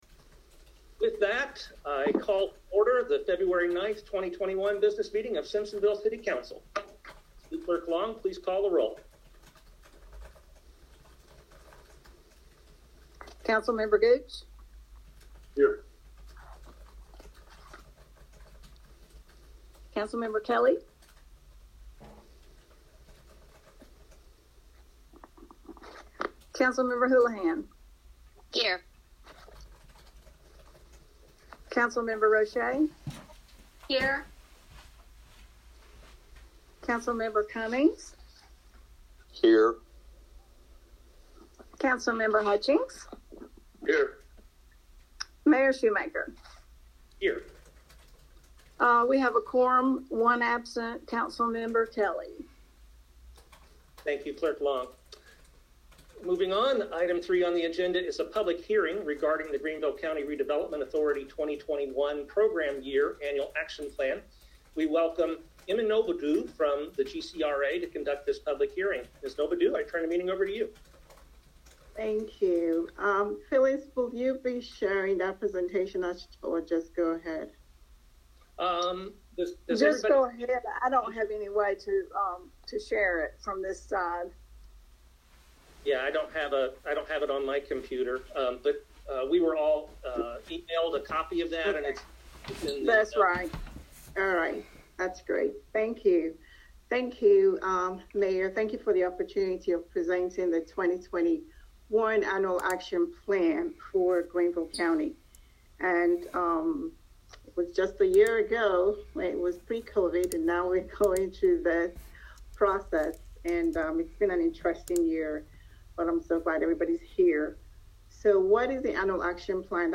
City Council Business Meeting